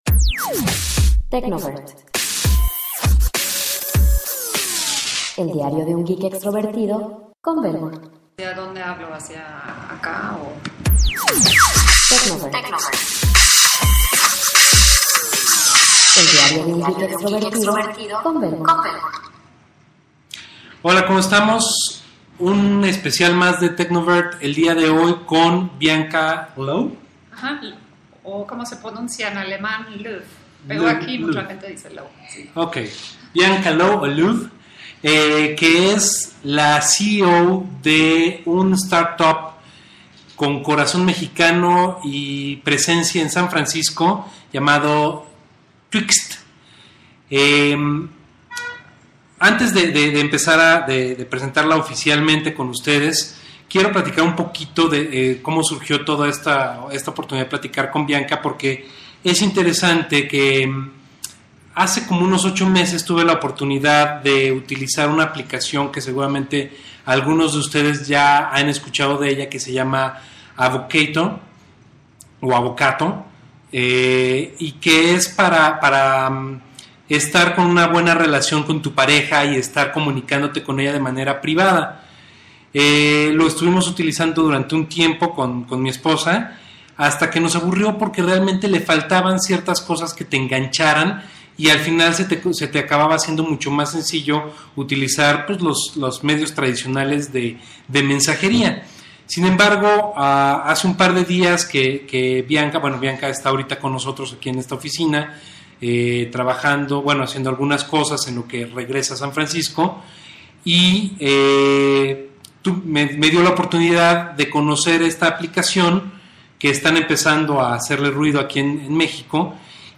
Tuve la oportunidad de tener una interesante plática